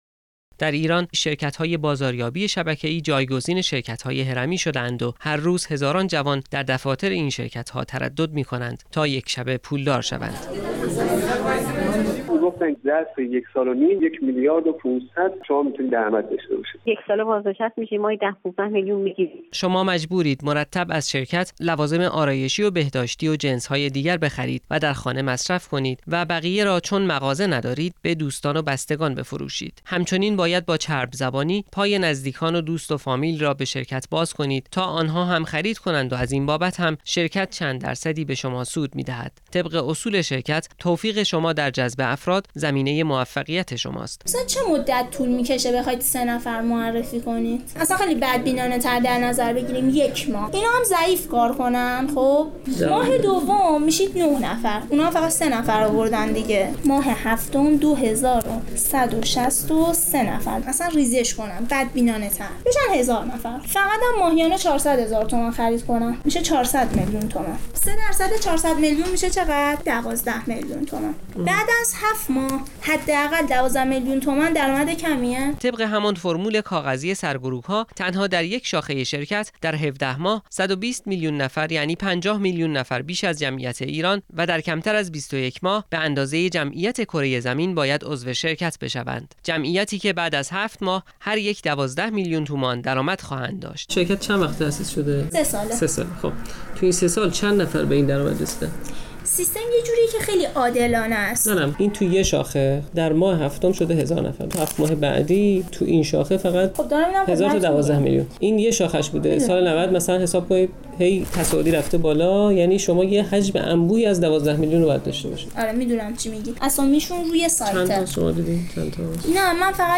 (برخی از مسئولان این شرکت‌ها چندی پیش با باشگاه خبرنگاران هم گفتگویی داشته اند.)